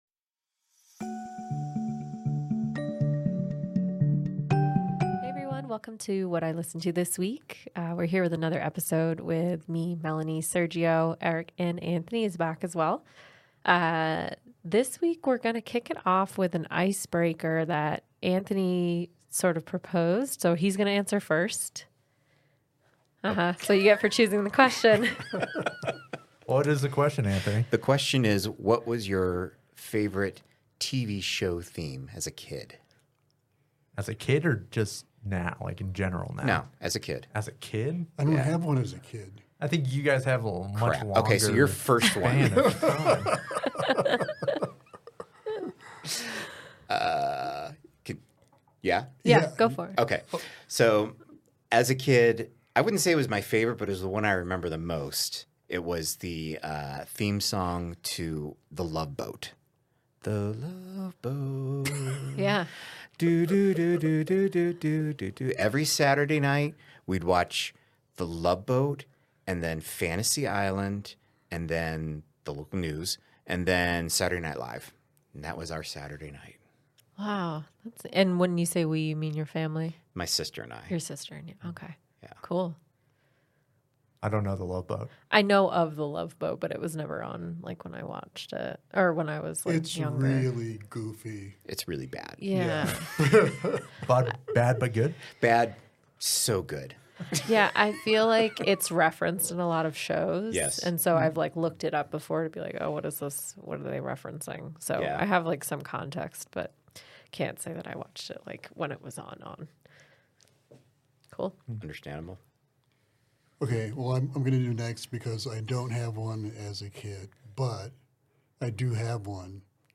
What I Listened to This Week is a show about music discovery, appreciation and discussion. Each panelist brings a song for the group to review and discuss.